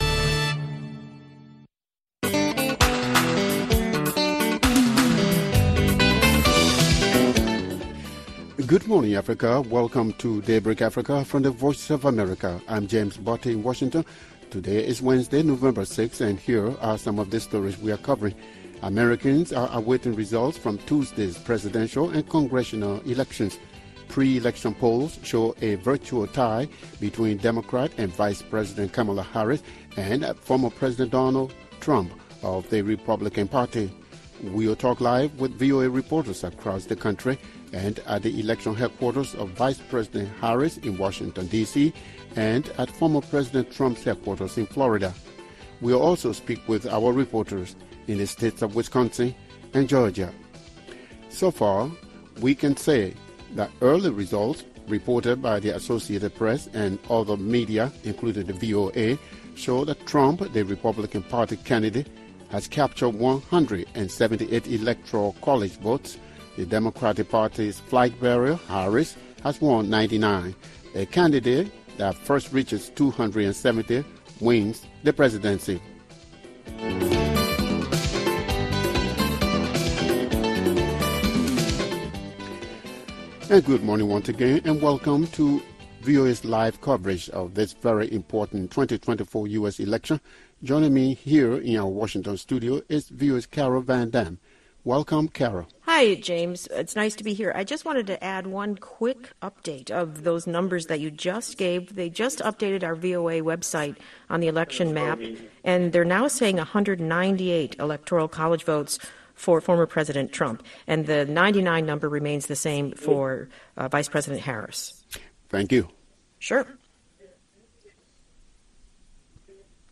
We’ll talk live with VOA reporters across the country, at the election headquarters of Vice President Harris in Washington DC, and at former President Trump’s headquarters in Florida. We’ll also speak with our reporters in the states of Wisconsin and Georgia.